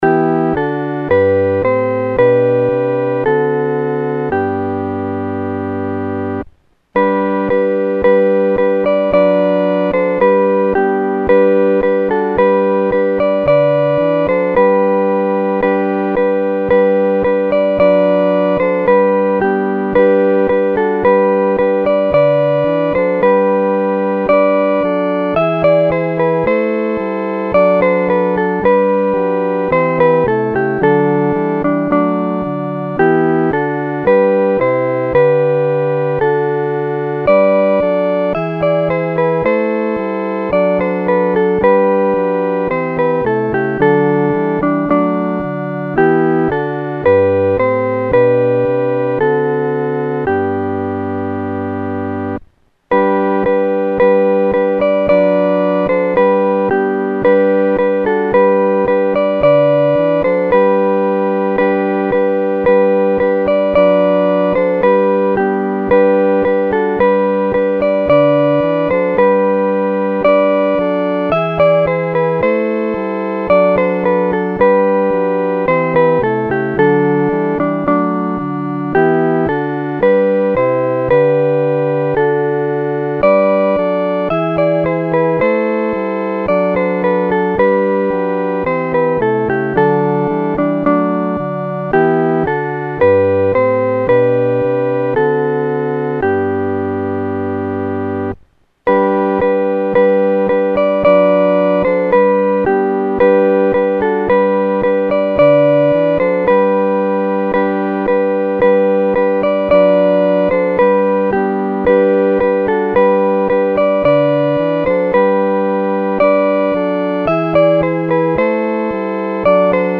合奏（四声部）
Traditional French Carol,1855